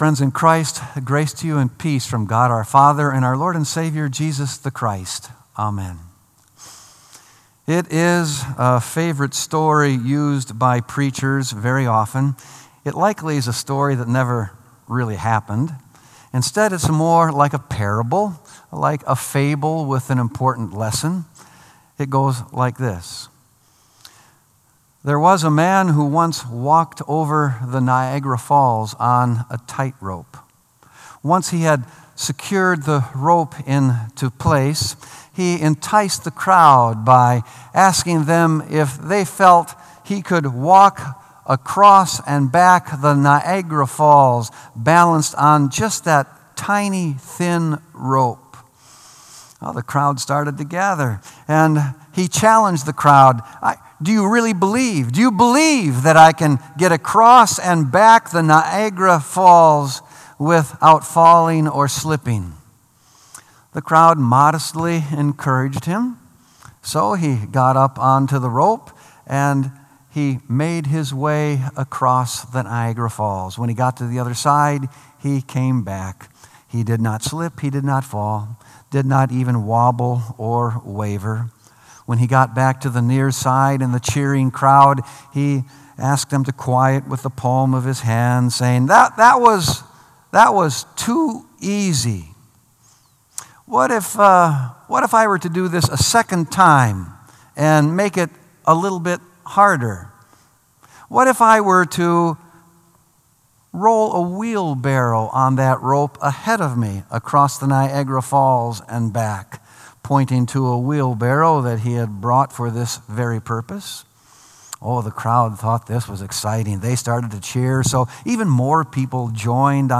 Sermon “That Man’s Got Faith!”